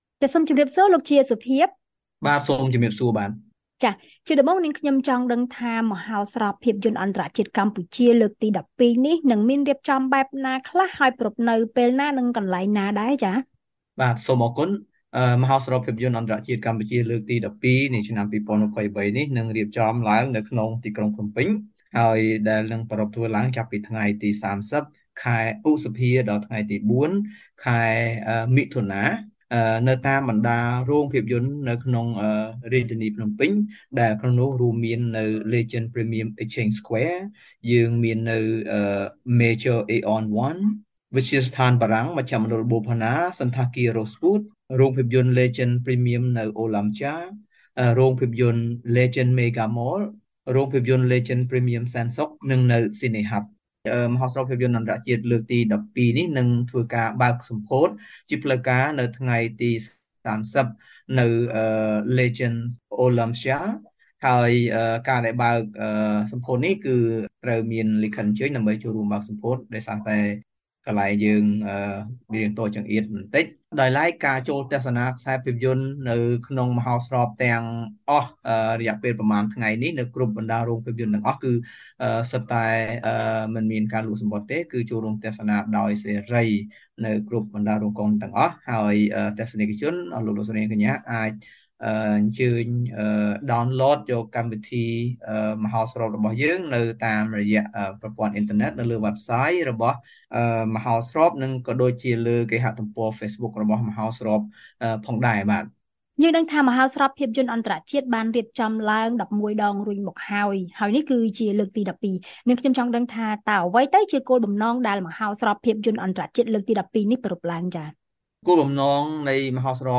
បទសម្ភាសន៍ VOA៖ ភាពយន្តចំនួន ១៨៨ នឹងត្រូវចាក់បញ្ចាំងក្នុងមហោស្រពភាពយន្តអន្តរជាតិកម្ពុជាលើកទី១២